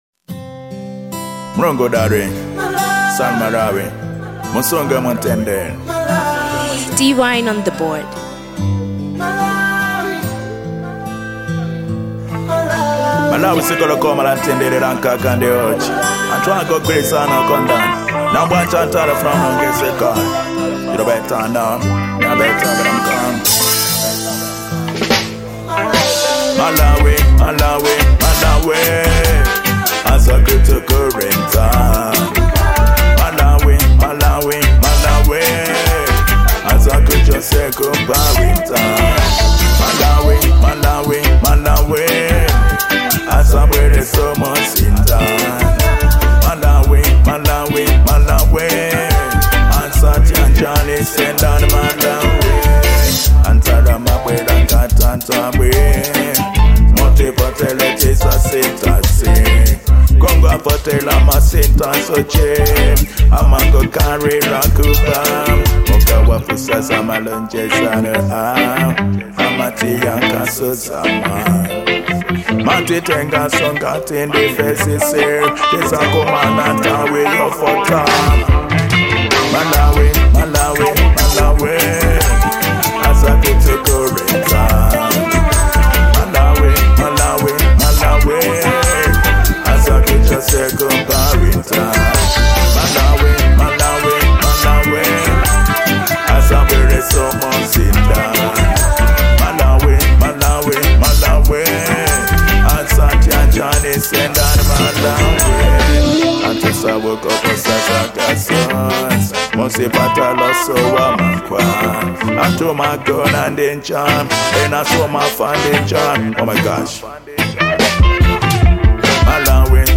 Genre : Reggie Dancehall